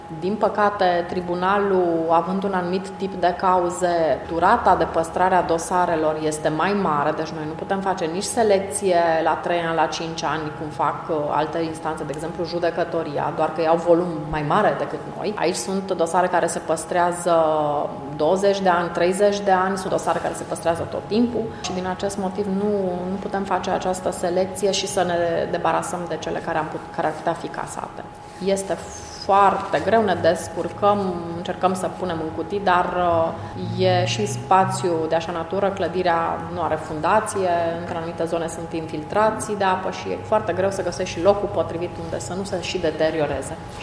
Preşedintele Tribunalului Mureş, Ioana Lucaci, a declarat că unele dosare trebuie păstrate termen îndelungat sau permanent, fapt care îngreunează și mai mult situația: